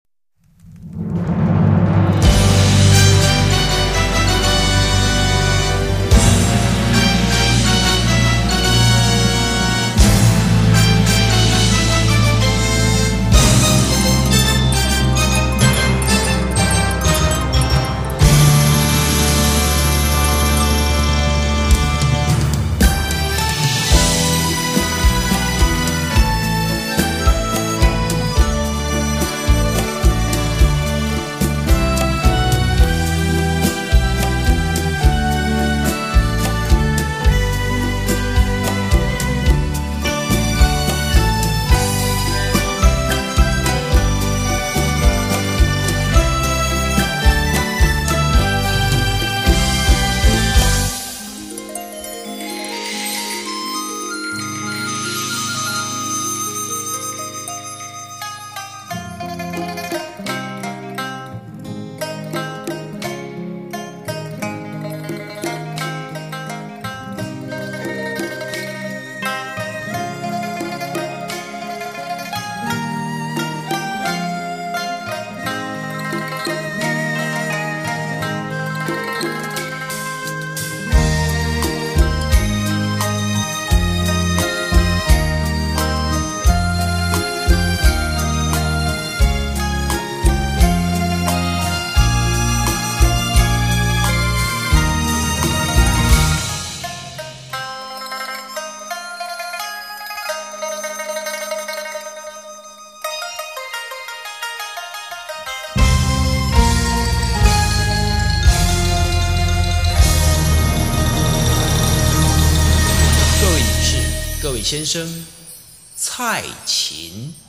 阔别红馆三载, 风采更胜当年